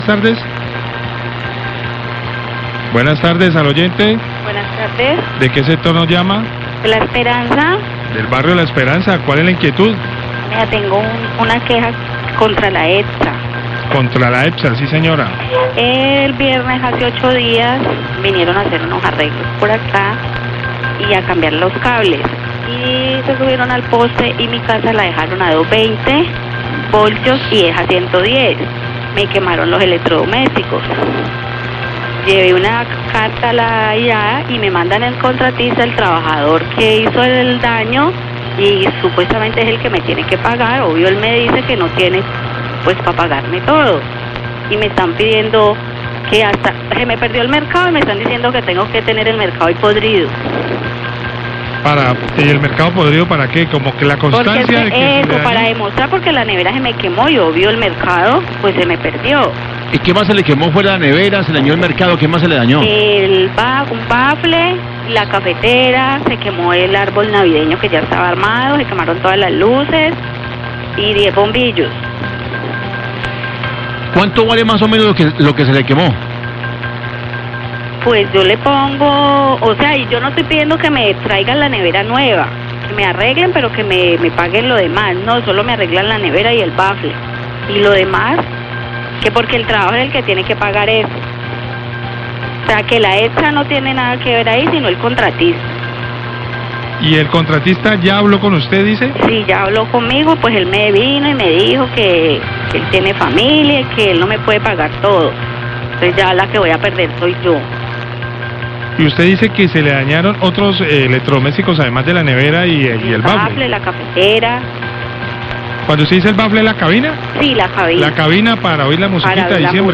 OYENTE DEL BARRIO LA ESPERANZA SE QUEJA DE LA EMPRESA EPSA, LA CARIÑOSA, 1206PM
Radio